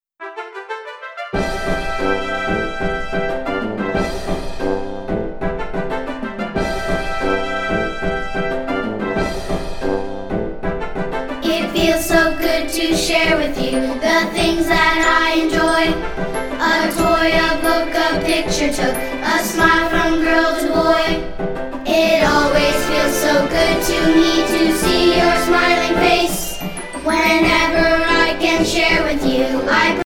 _ The full-length music track with vocals.